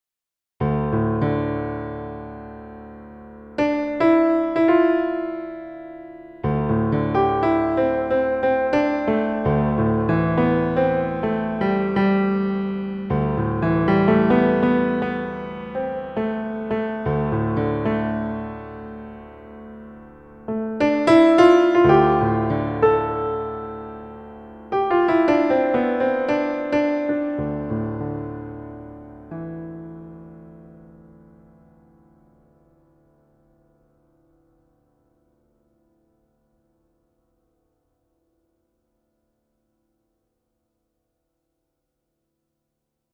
Now your right-hand improvisation has a sonic landscape to play in, rather than a just a single note to bounce off.
Modal Improv over Rich D Drone
Modal-Improv-6.mp3